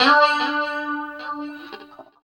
29 GUIT 6 -R.wav